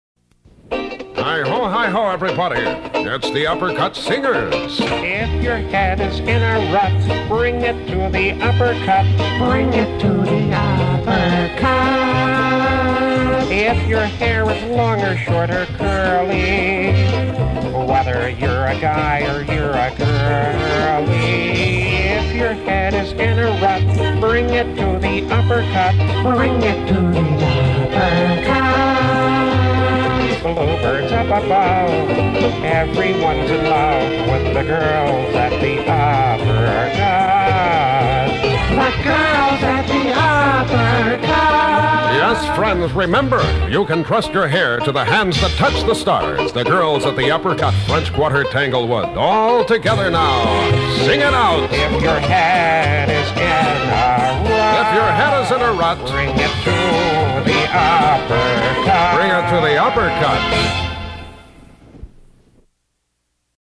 The Upper Cut Commercial #1